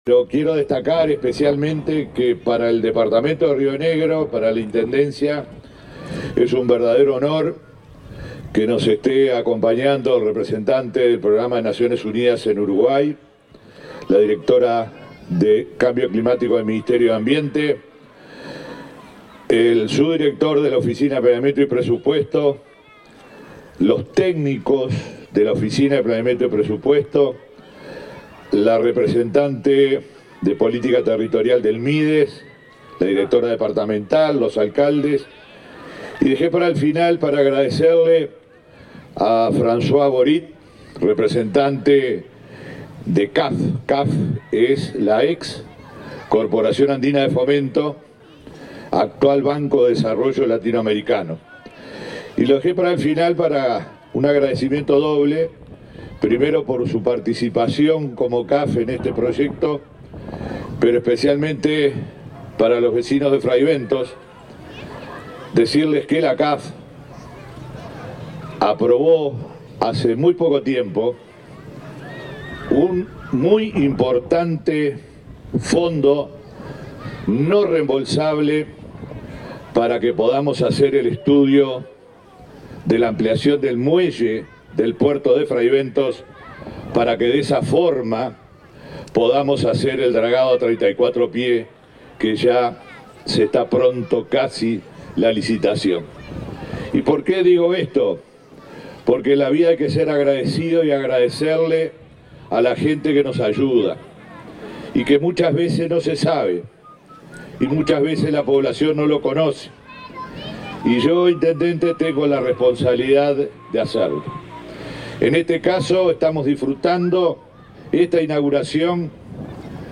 Acto de inauguración de obras en espacio público de Fray Bentos
El Ministerio de Ambiente, la Oficina de Planeamiento y Presupuesto (OPP) y la Intendencia de Río Negro inauguraron, este 8 de agosto, las obras de un espacio interactivo en el parque La Esmeralda, en la ciudad de Fray Bentos. Participaron en el acto el intendente Omar Lafluf y la directora nacional de Cambio Climático, Natalie Pareja.